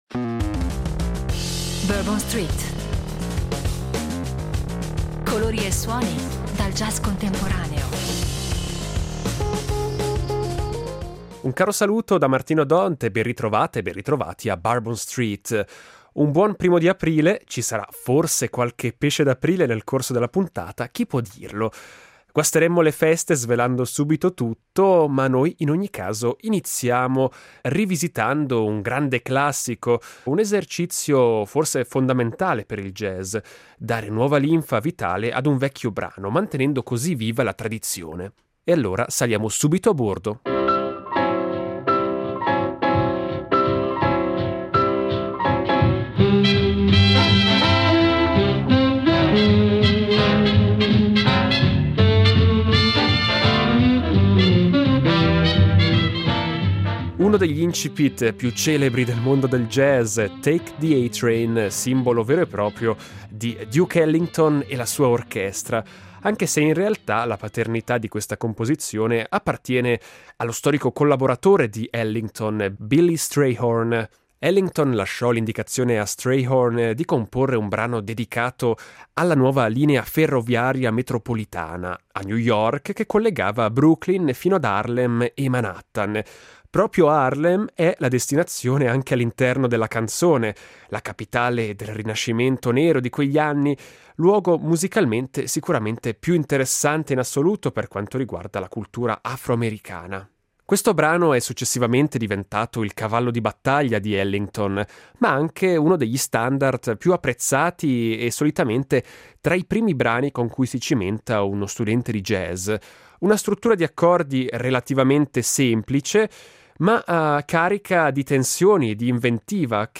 Il jazz si racconta tra ispirazioni sorprendenti e nuove interpretazioni